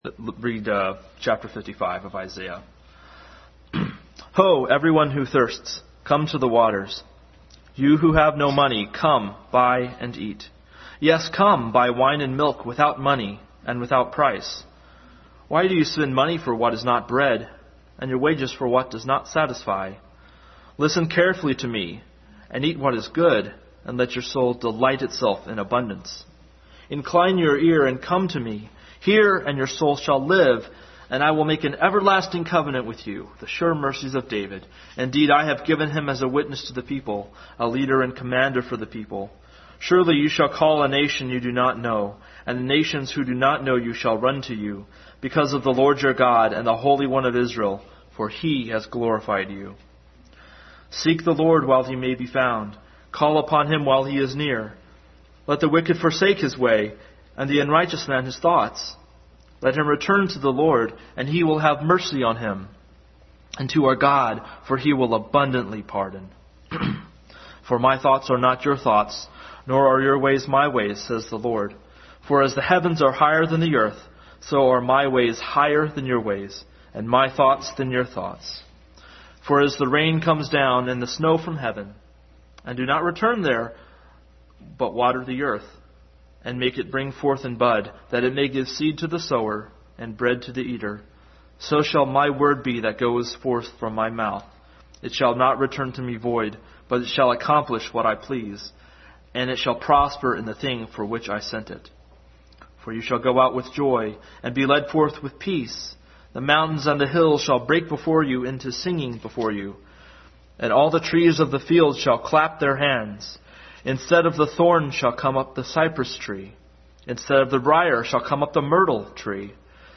Isaiah 55:1-13 Passage: Isaiah 55:1-13, 1 Corinthians 1:23-25, Matthew 22:1-14 Service Type: Family Bible Hour